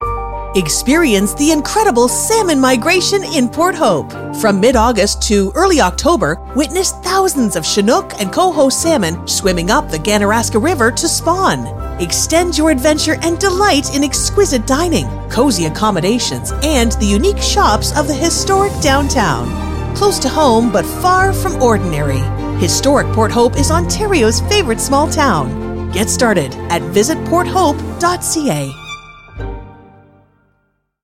Listen to an example of an ad about the Salmon run .
Radio Spot 4.mp3